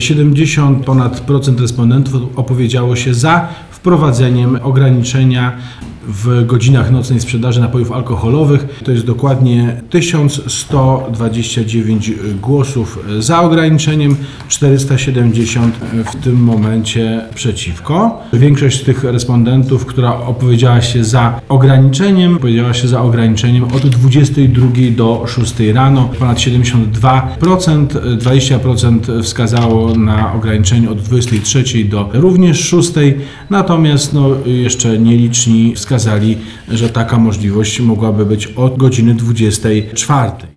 We wtorek, 13 stycznia, w płockim ratuszu odbyła się konferencja prasowa poświęcona wynikom konsultacji społecznych dotyczących nocnej sprzedaży alkoholu na terenie miasta.
– Mówił Prezydent Miasta Płocka Andrzej Nowakowski.